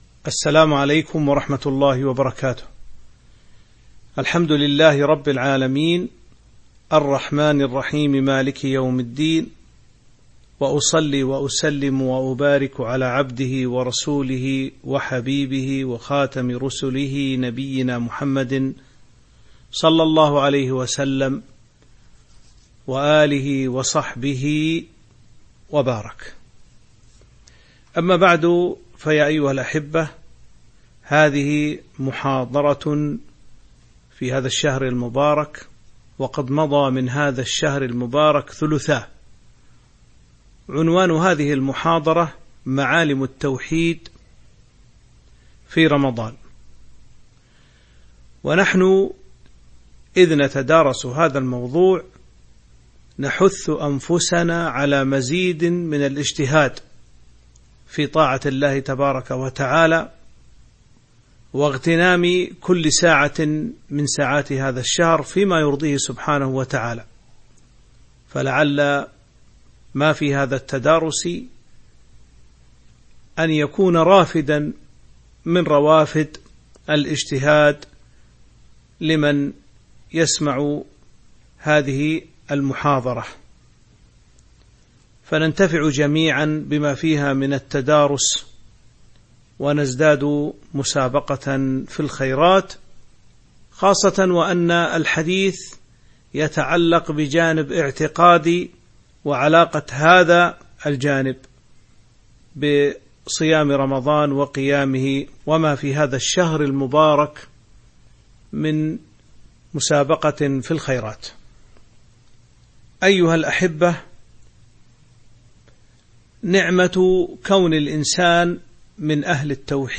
تاريخ النشر ٢٠ رمضان ١٤٤٤ هـ المكان: المسجد النبوي الشيخ